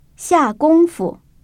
[xià gōng‧fu] 시아꿍푸